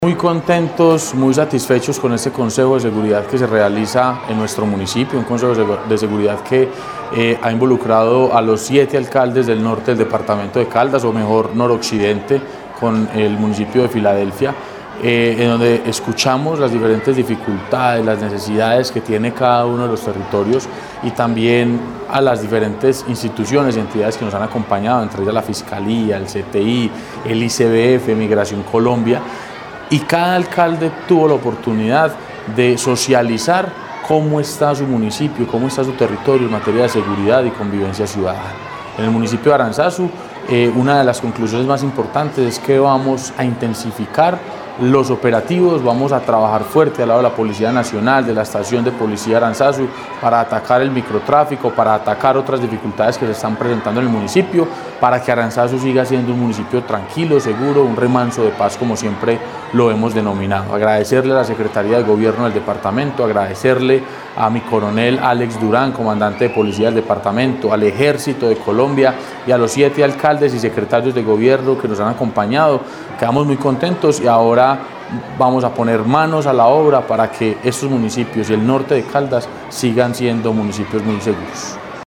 Sebastián Merchán, alcalde de Aranzazu
Sebastian-Merchan-alcalde-de-Aranzazu.mp3